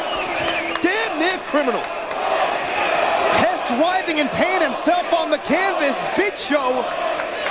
Was there any doubt – ANY DOUBT – that a “
Bullshit” chant was about to start?